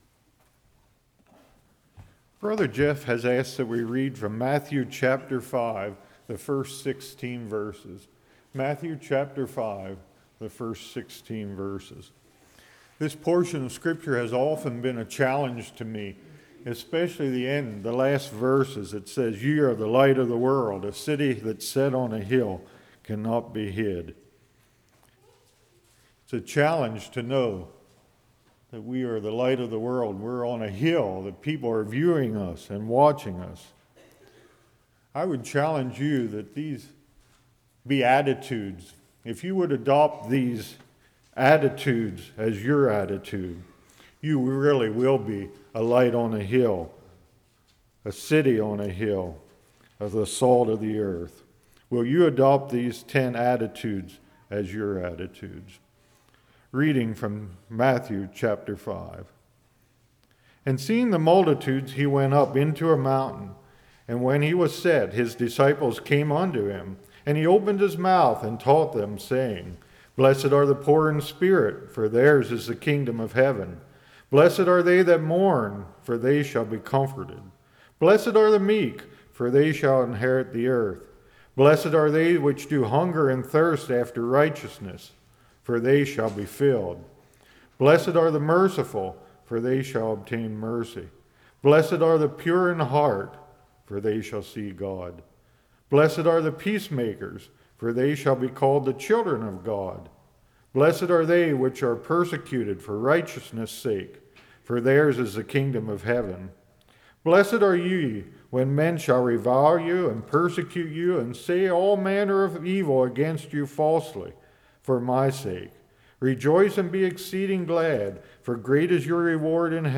Matthew 5:1-15 Service Type: Evening Darkness v Light Ye are the Light of the World Don’t hide the Light « Content or Wanting More?